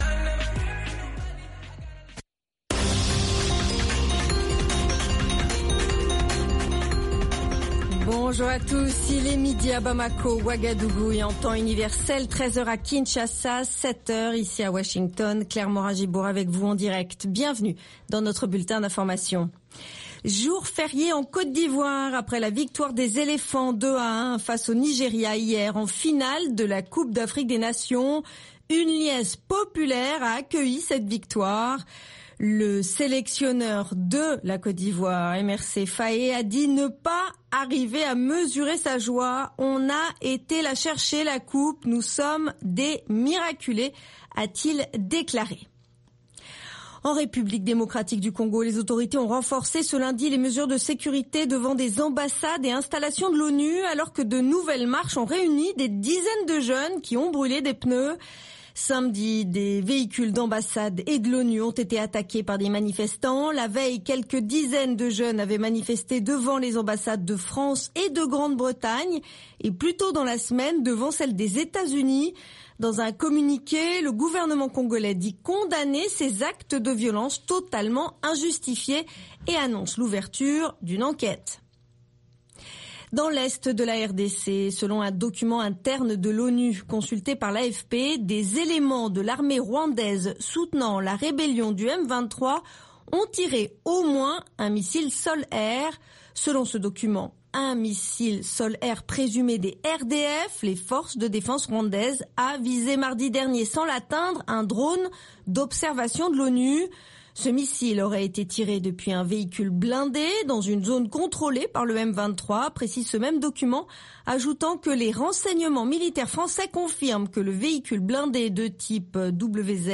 Bulletin d'information de 16 heures